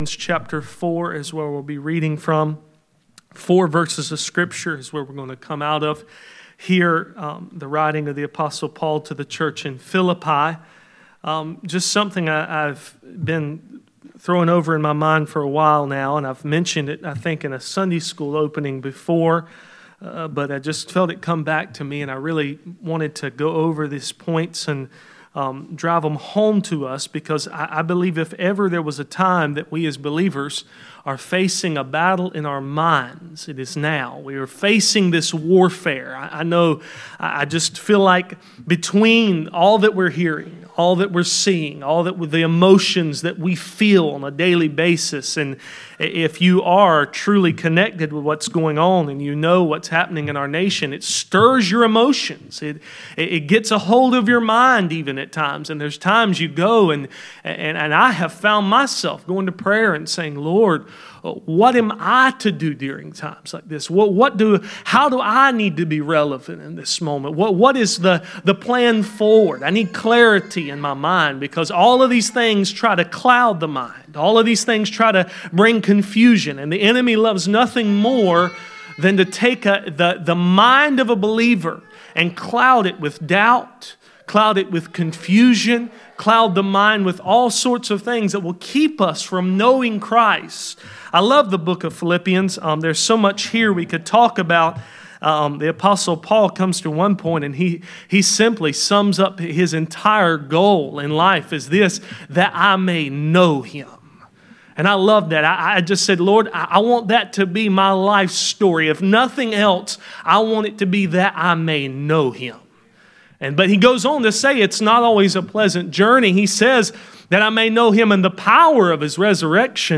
Philippians 4:4-8 Service Type: Midweek Meeting %todo_render% « The Love that God hates